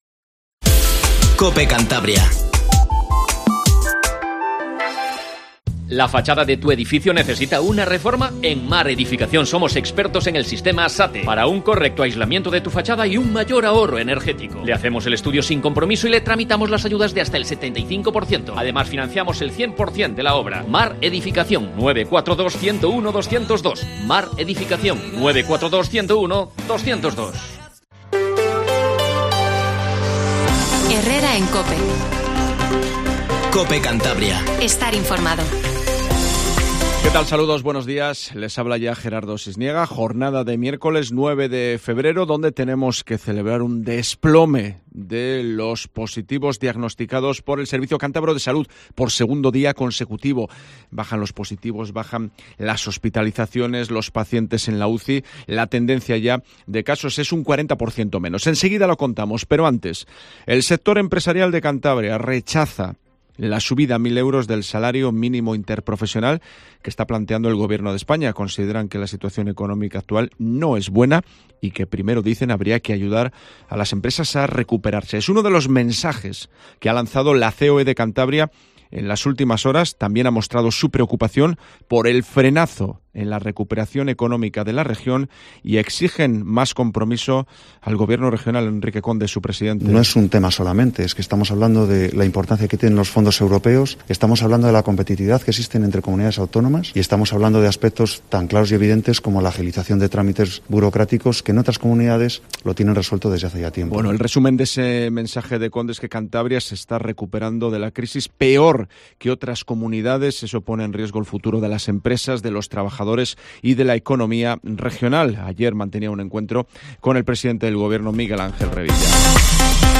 Informativo Matinal COPE CANTABRIA